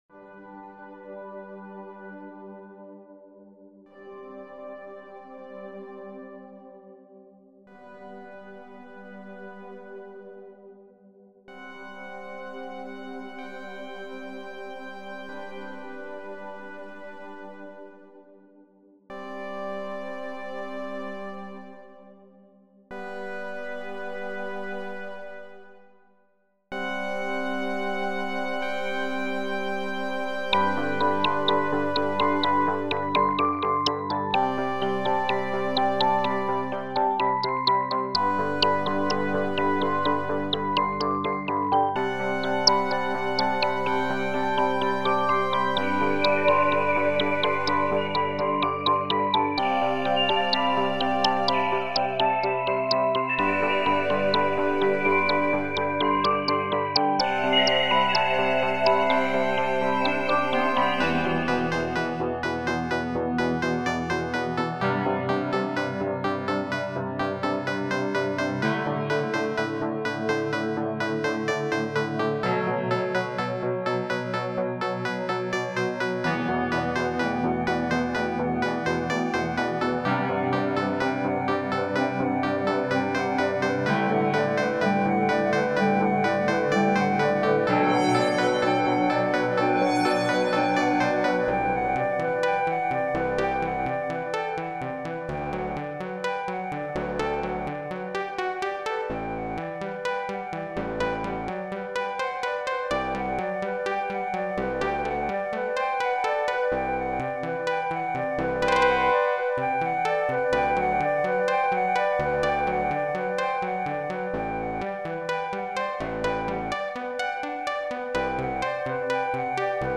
Really pretty soft pads, almost like a keyboard synth of some kind. Really loving the slow reverb fade, really going for something dynamic. Loving the bird synth and the chords, it's quite uplifting and happy.
Towards the middle i felt it got into more of a FM slant, but the overall sound is actually lovely.